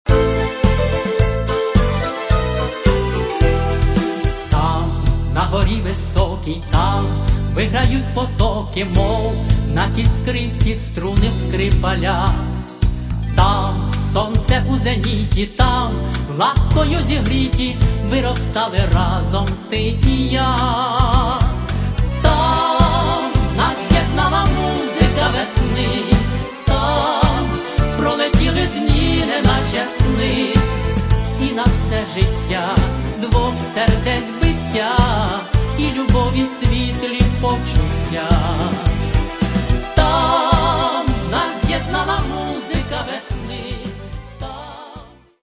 Каталог -> Естрада -> Співаки